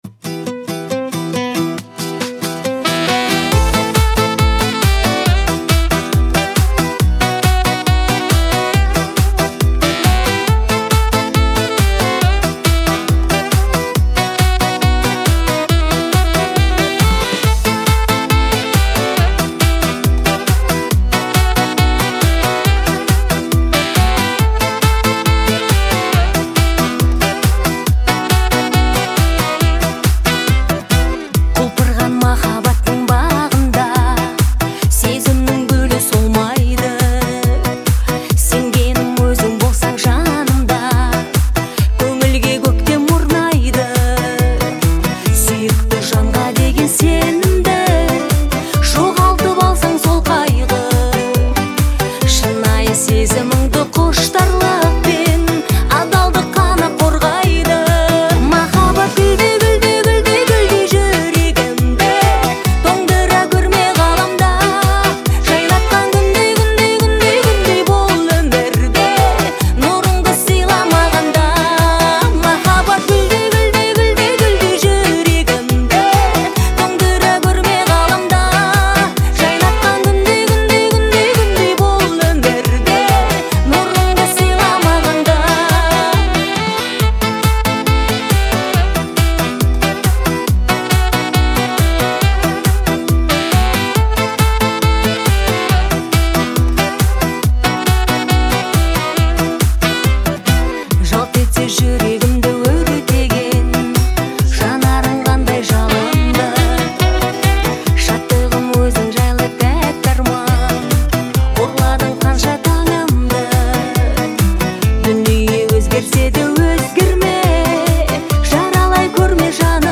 это яркий образец казахской эстрадной музыки